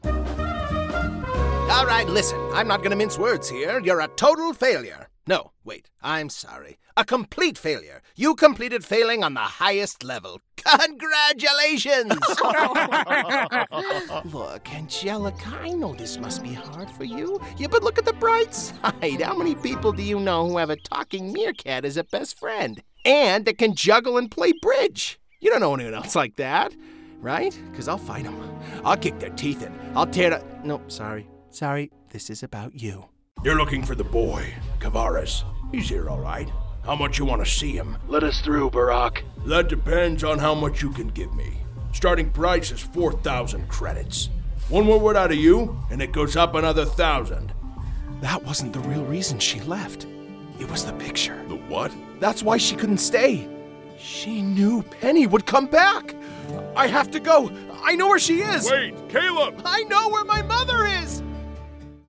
Professionally-built, broadcast quality, double-walled LA Vocal Booth.